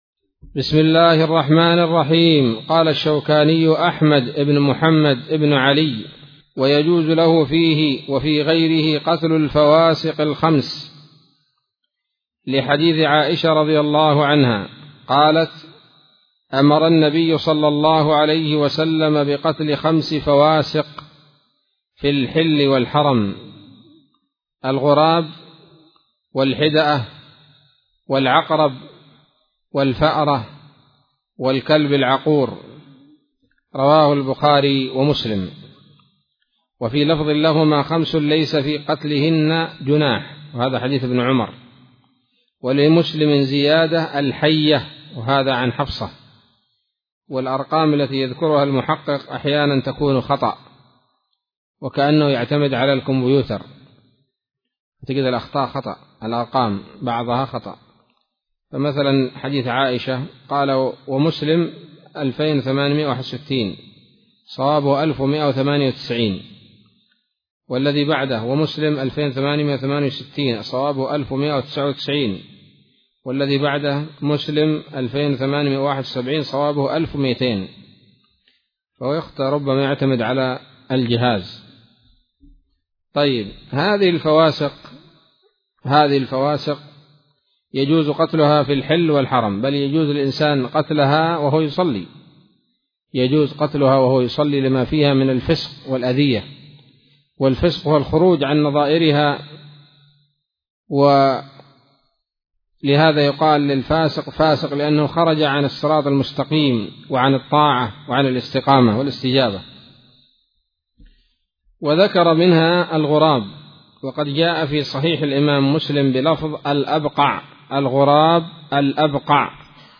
الدرس السابع من كتاب الحج من السموط الذهبية الحاوية للدرر البهية